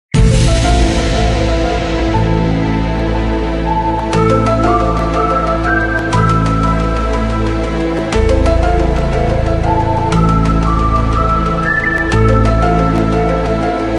알림음 8_Popular_43.ogg